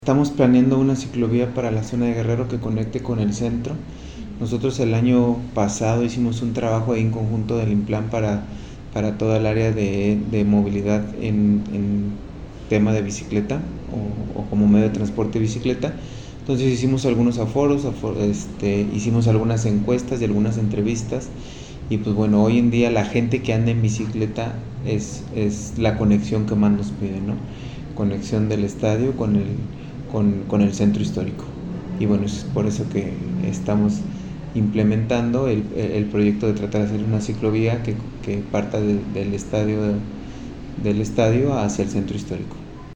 AudioBoletines
Alfredo Torres Nohra, director de movilidad